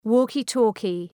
{,wɔ:kı’tɔ:kı}